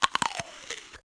eatcrnch.mp3